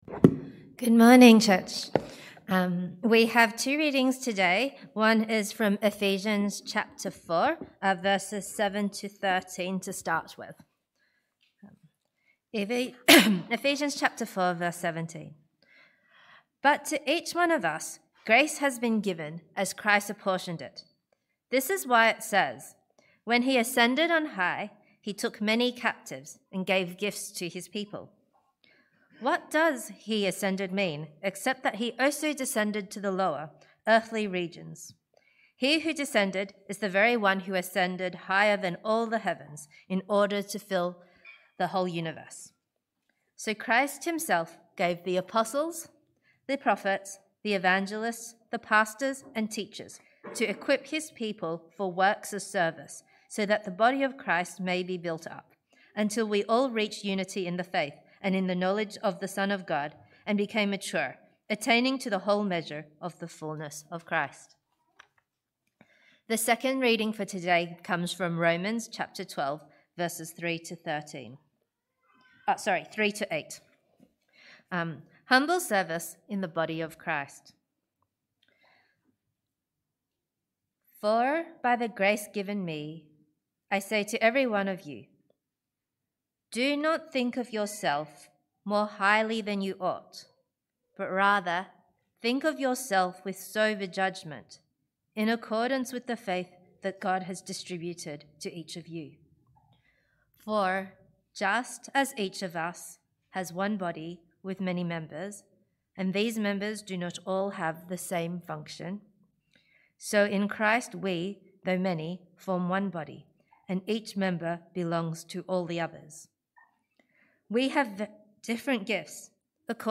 Sermons | Dickson Baptist Church